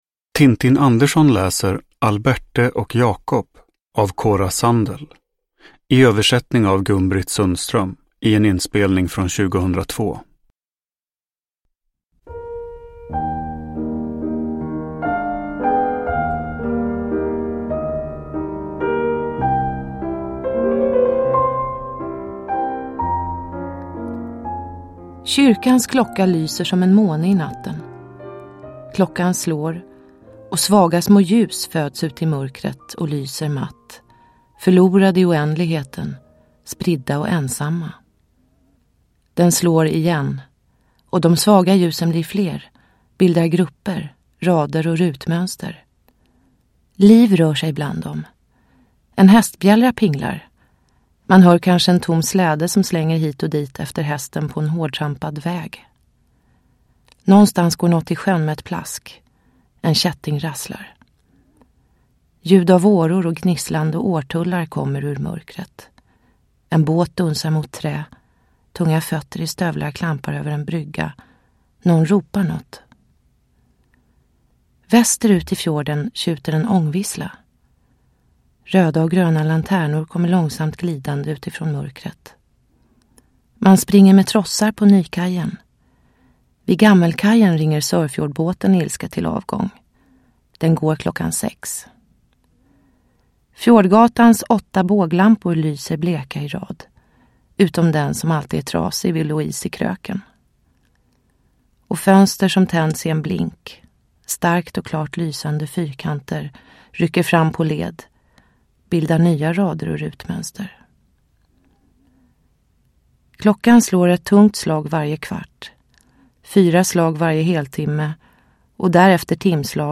Alberte och Jakob – Ljudbok – Laddas ner
Uppläsare: Tintin Anderzon